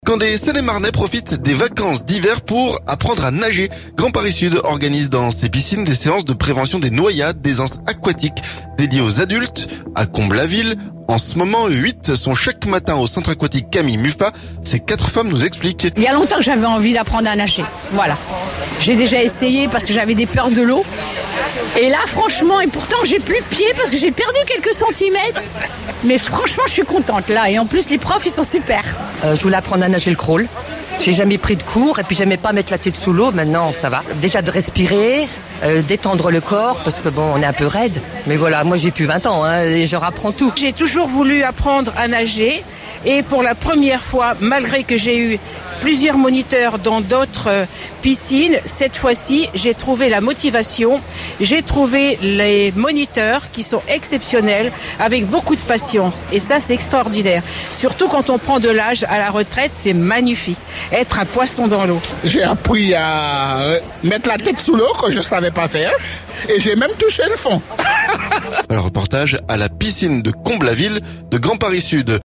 Ces quatre femmes nous expliquent.